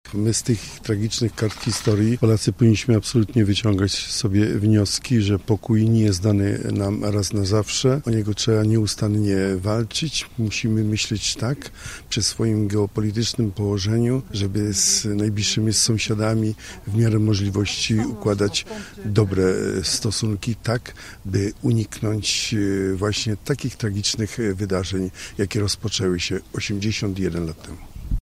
Od złożenia wiązanek kwiatów i zapalenia znicza pod pomnikiem upamiętniającym ofiary II wojny światowej na gorzowskim cmentarzu rozpoczęły się uroczystości związane z 81. rocznicą wybuchu II wojny światowej.
Jak powiedział nam wojewoda lubuski Władyslaw Dajczak, tej daty nigdy nie możemy zapomnieć: